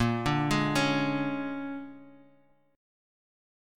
Bb7#9 chord